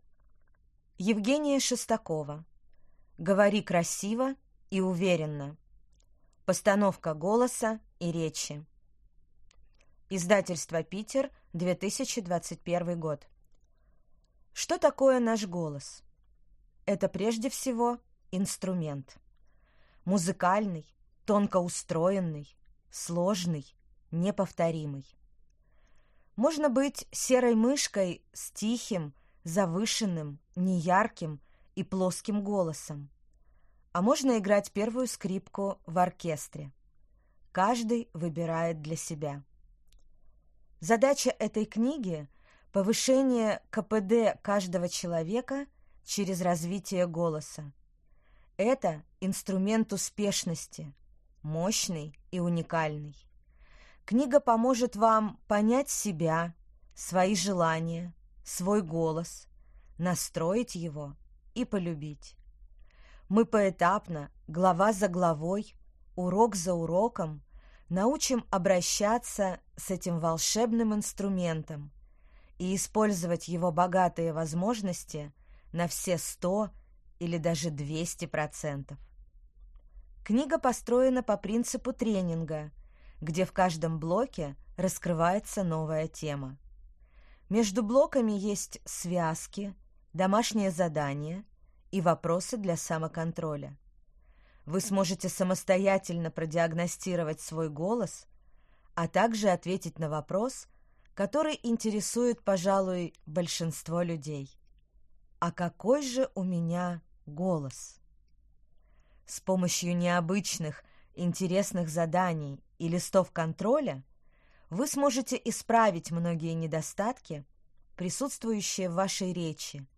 Аудиокнига Говори красиво и уверенно. Постановка голоса и речи | Библиотека аудиокниг